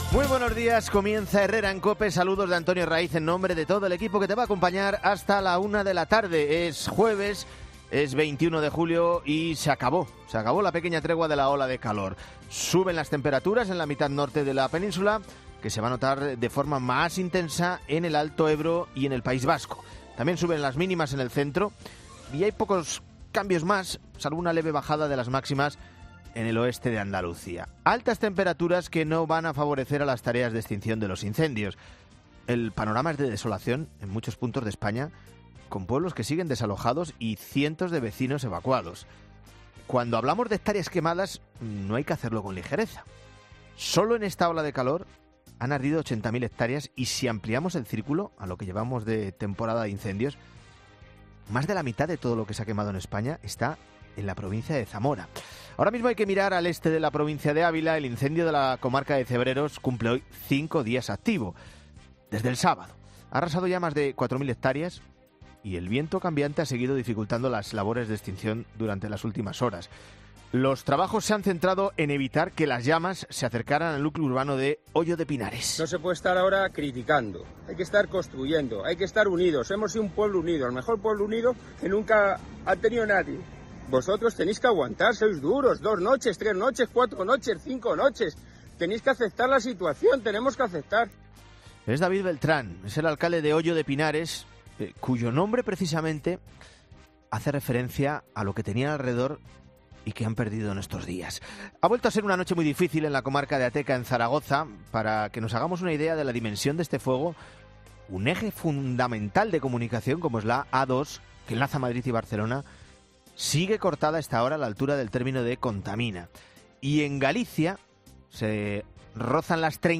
analiza los principales titulares que marcarán la actualidad de este jueves 21 de julio en España